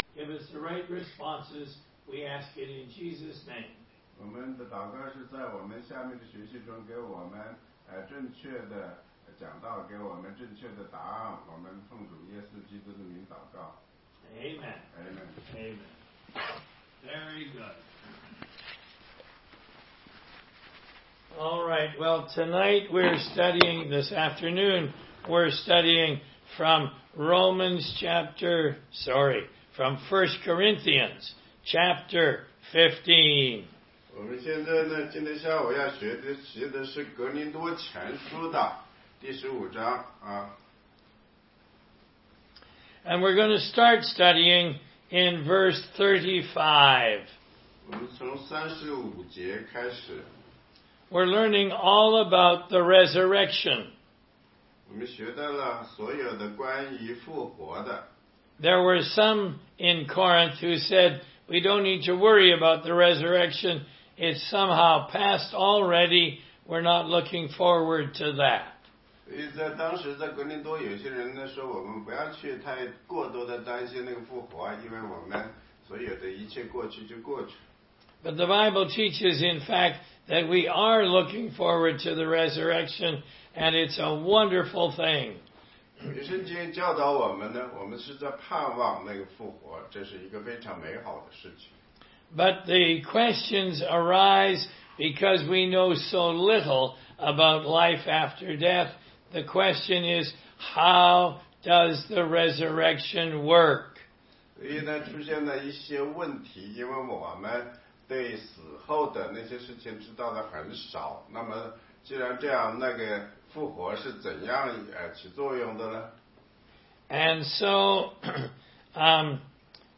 16街讲道录音 - 哥林多前书15章35-49节：所种的是必朽坏的，复活的是不朽坏的